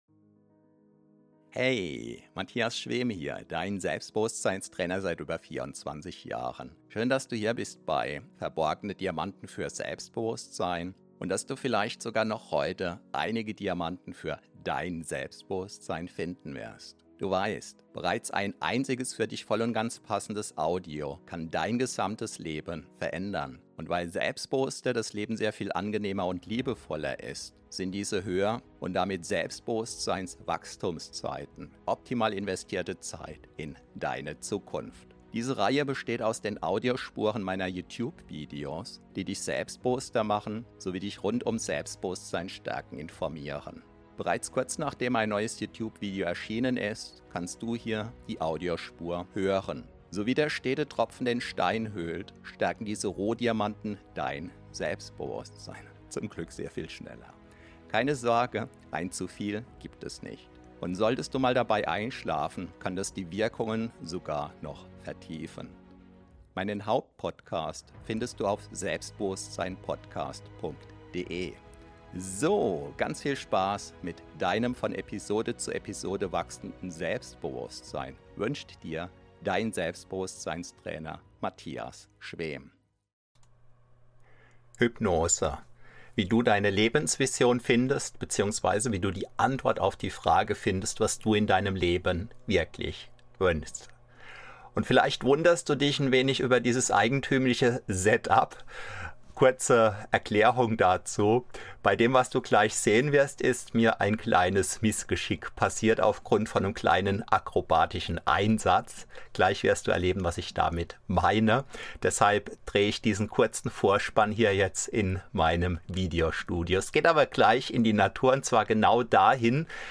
Hypnose: Wie du deine Lebensvision findest | Herausfinden was du willst | Für Perfektionisten | XXL ~ Verborgene Diamanten Podcast [Alles mit Selbstbewusstsein] Podcast